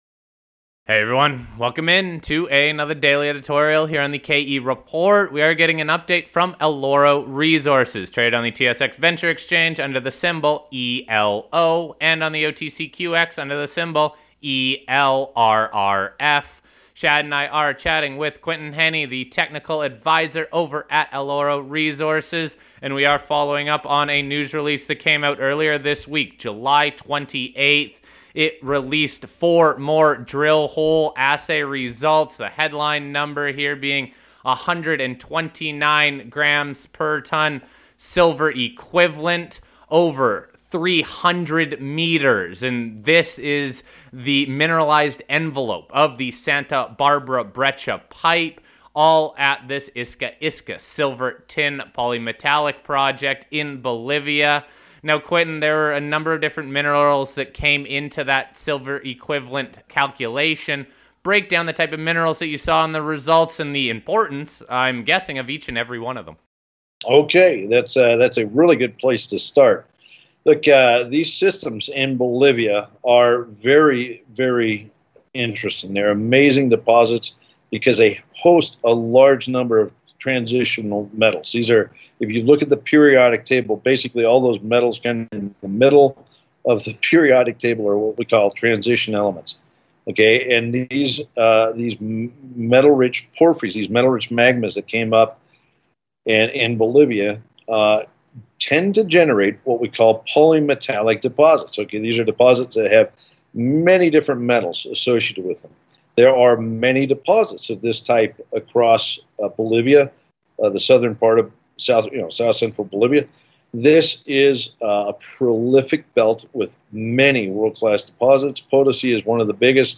We wrap up the interview with a look at the ongoing drill program that includes 3 rigs and where these drills